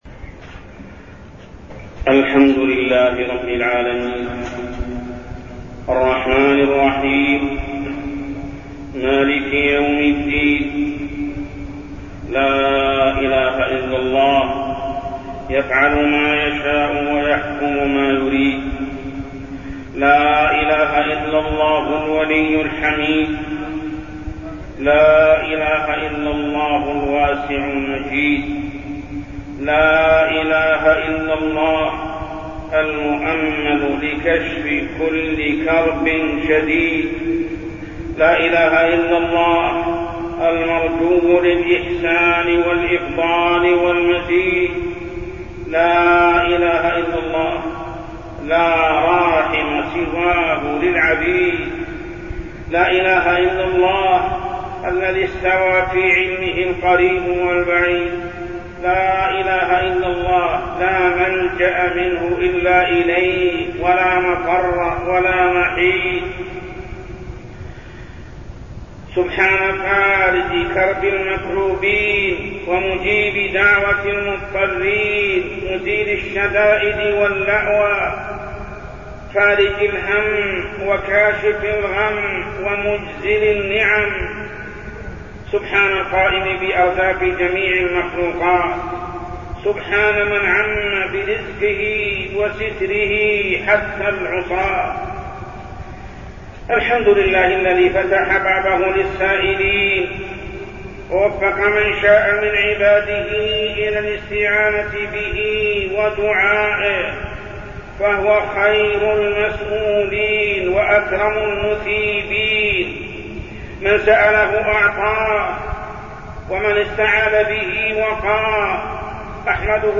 تاريخ النشر ٦ شعبان ١٤٠٩ هـ المكان: المسجد الحرام الشيخ: محمد بن عبد الله السبيل محمد بن عبد الله السبيل أسباب منع القطر